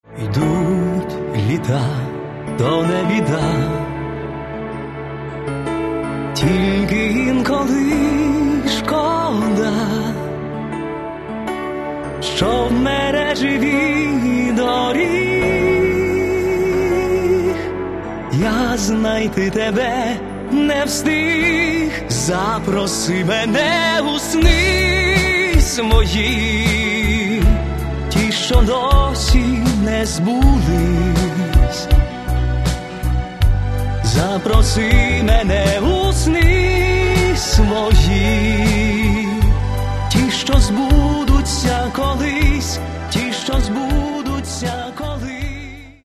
в исполнении современных поп-артистов.